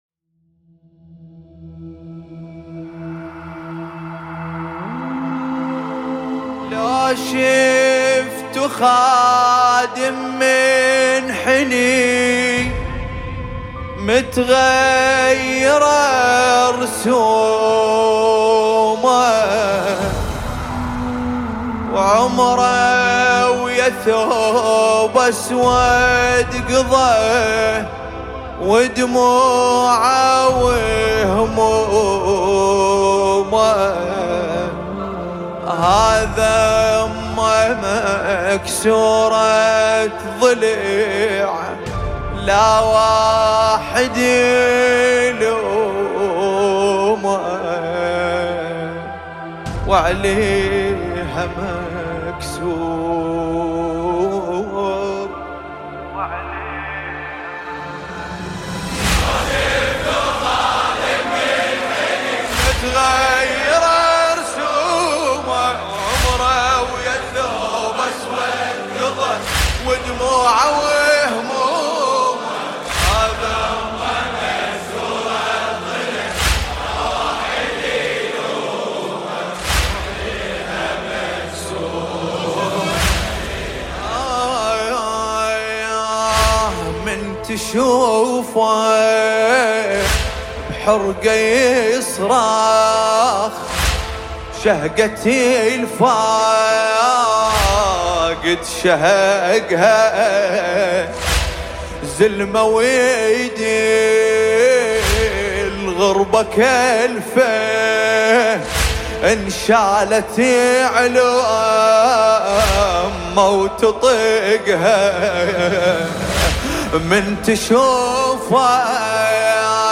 مداحی عربی